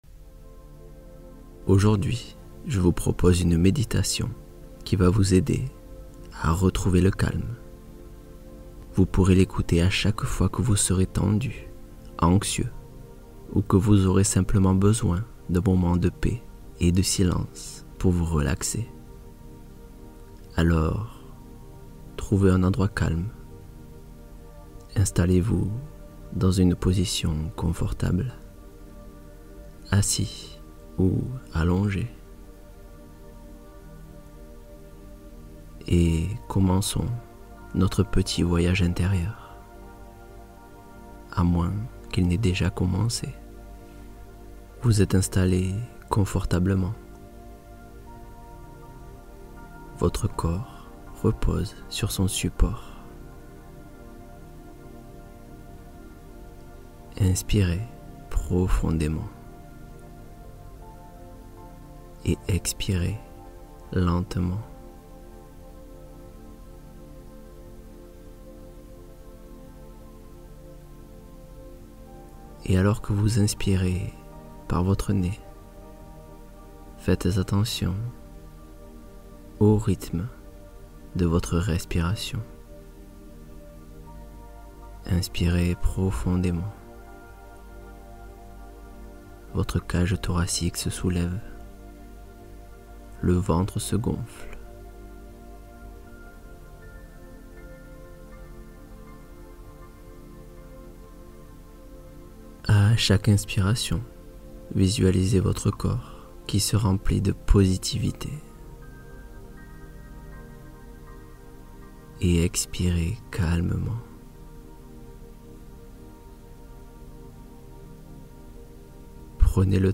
Migraines relâchées par la pleine détente — Méditation ciblée